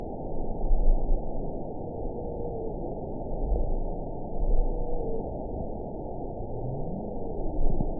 event 922395 date 12/30/24 time 08:36:03 GMT (11 months ago) score 8.58 location TSS-AB10 detected by nrw target species NRW annotations +NRW Spectrogram: Frequency (kHz) vs. Time (s) audio not available .wav